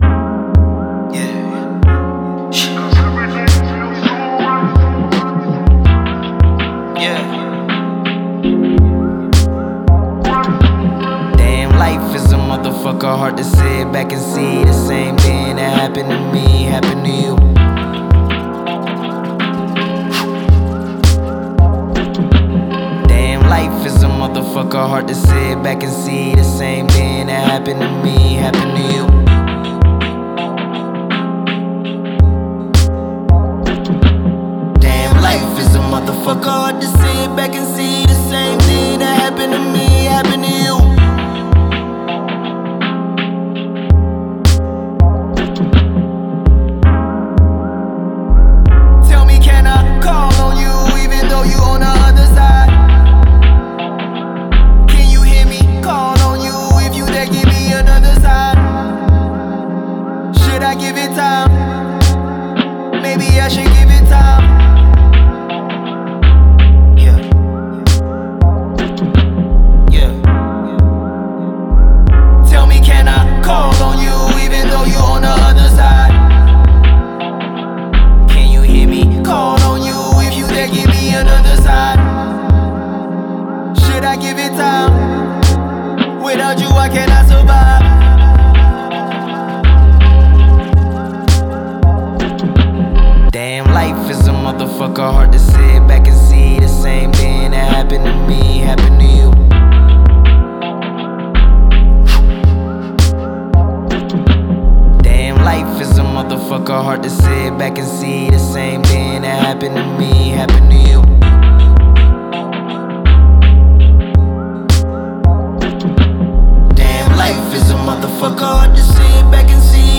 Genre: R&B/Soul/HipHop